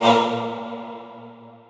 TCE tm88 Choir Stab.wav